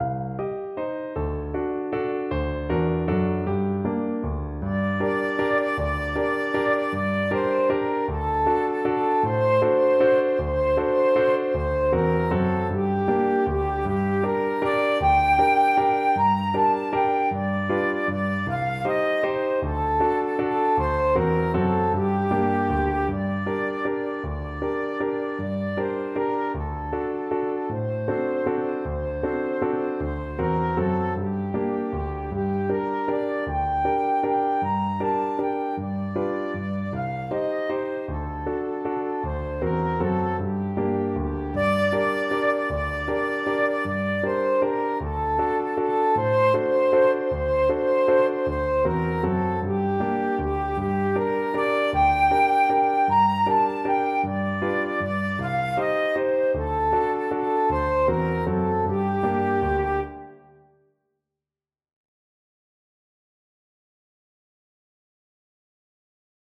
Traditional Trad. Tumbalalaika Flute version
Flute
Tumbalalaika is a Russian Jewish folk and love song in the Yiddish language.
3/4 (View more 3/4 Music)
G5-Bb6
G minor (Sounding Pitch) (View more G minor Music for Flute )
One in a bar . = c.52
Traditional (View more Traditional Flute Music)